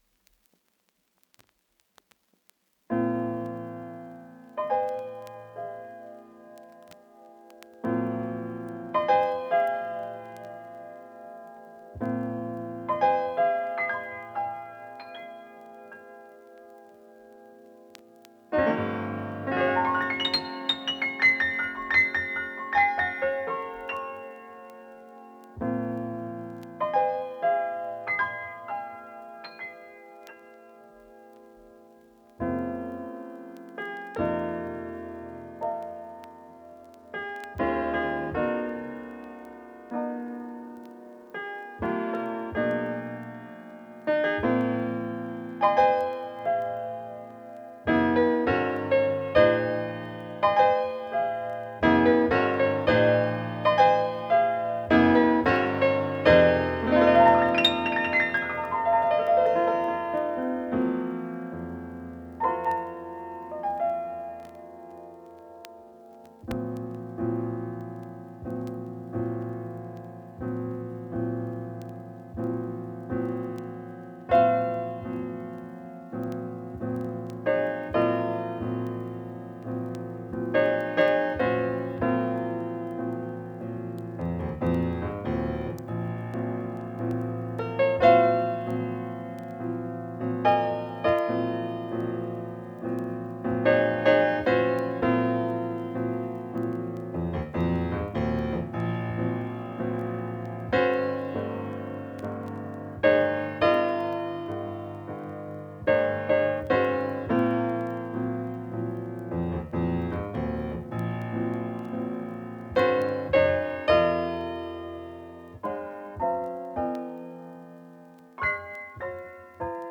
Jazz-Rock,_Fusion_)[LP